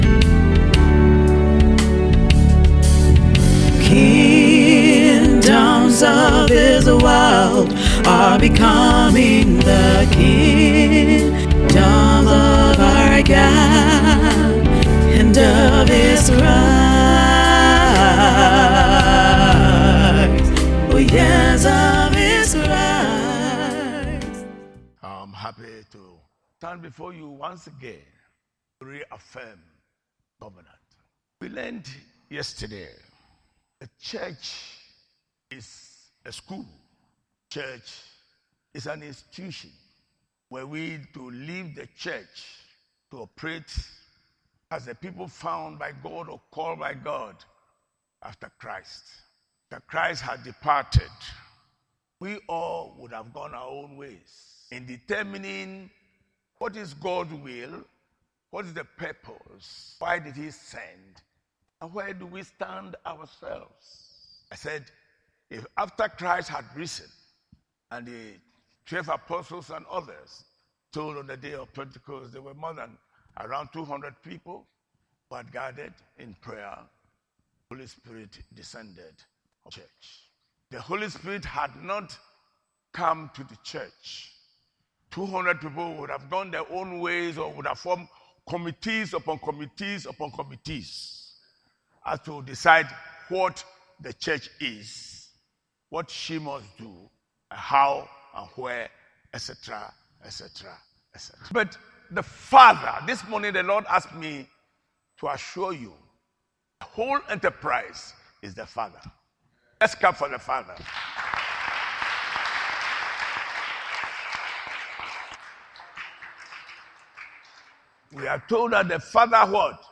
Series: Audio Sermon